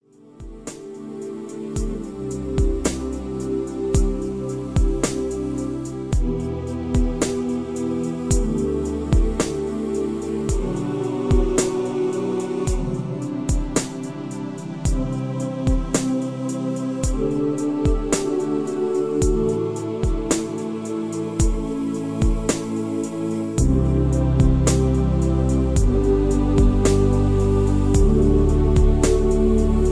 (Key-Em) Karaoke MP3 Backing Tracks